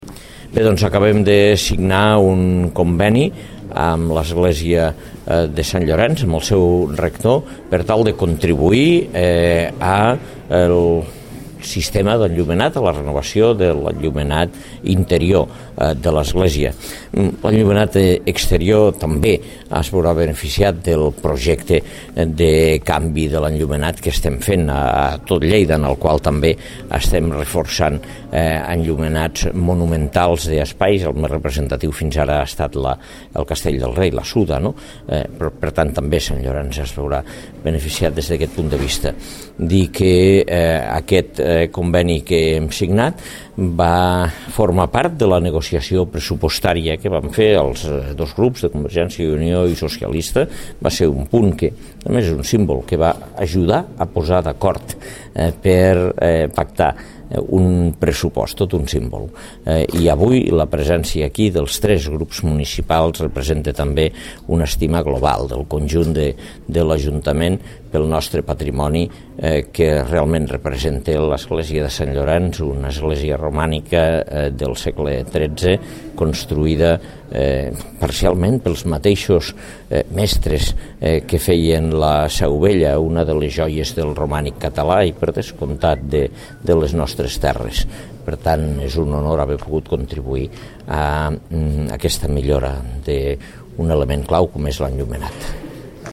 Inclou tall de veu de l’alcalde de Lleida, Àngel Ros.
tall-de-lalcalde-de-lleida-angel-ros-sobre-la-renovacio-i-millora-de-lenllumenat-de-lesglesia-de-sant-llorenc